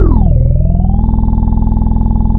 FOXXY BASS-L.wav